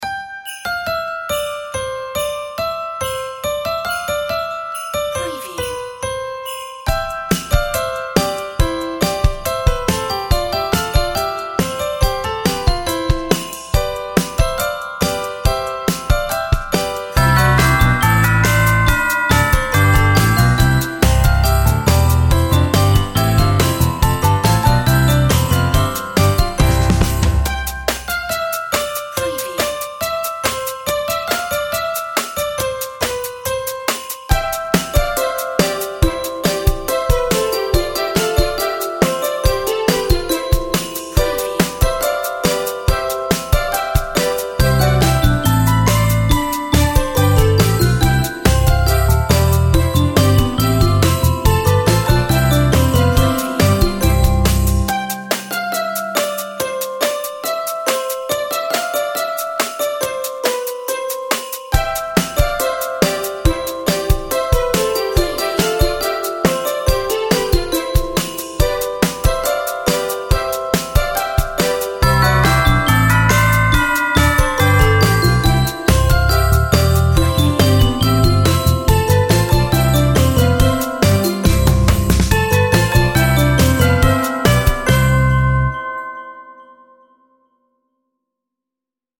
Upbeat chrsitmas